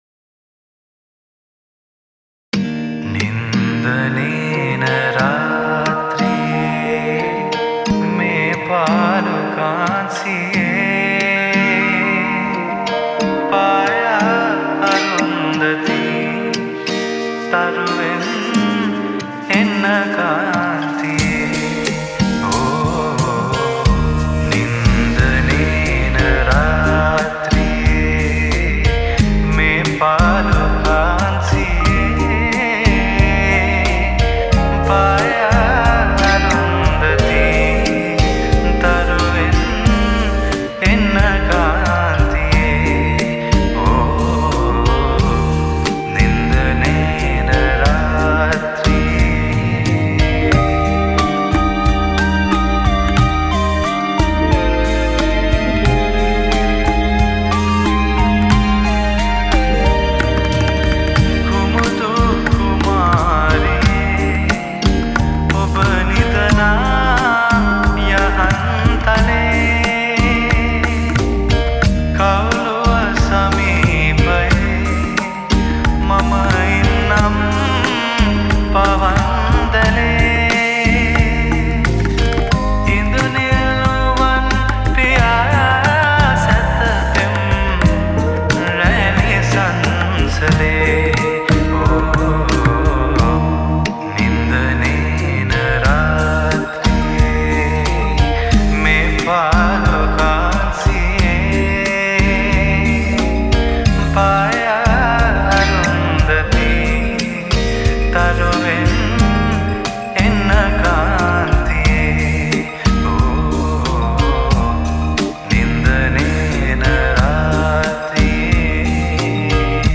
Lofi
Cover Vocals
Thabla & Dhol